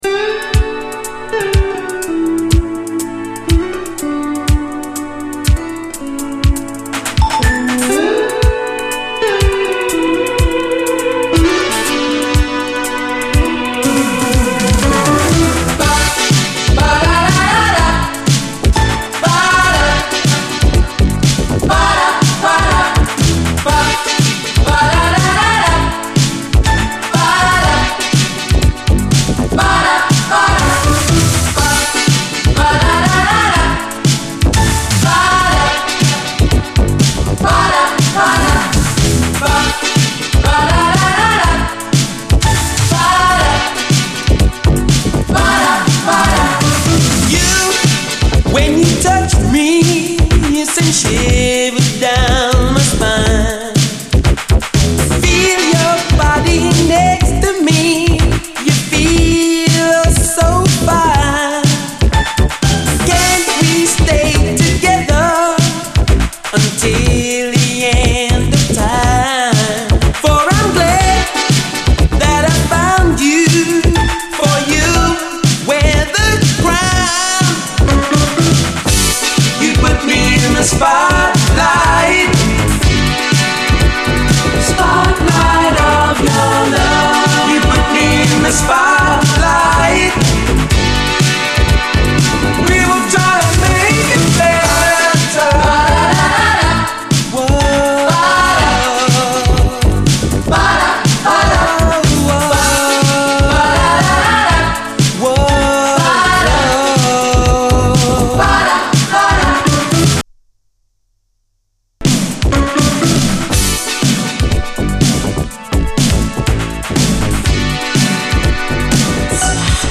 SOUL, 70's～ SOUL, DISCO
レア＆マイナーな最高ブリット・ファンク〜UKシンセ・ブギー！シンセ＆パララ・コーラスが超爽快に突き抜ける！